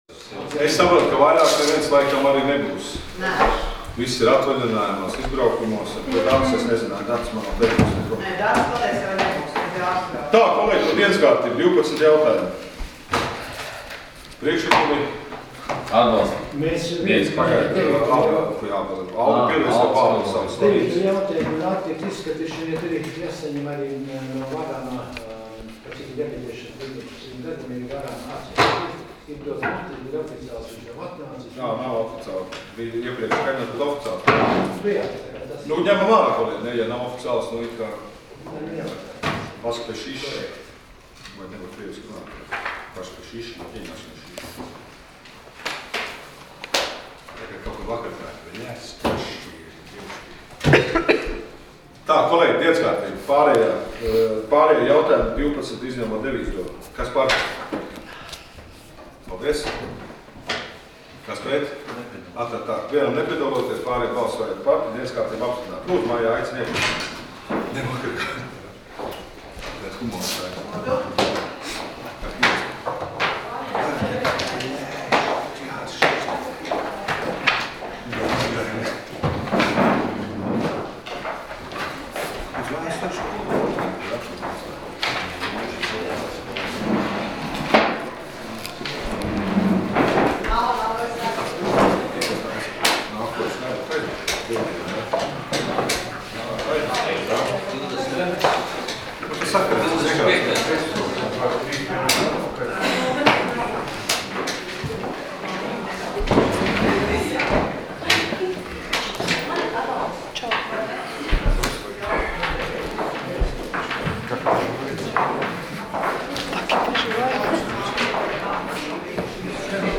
Domes sēdes 11.03.2016. audioieraksts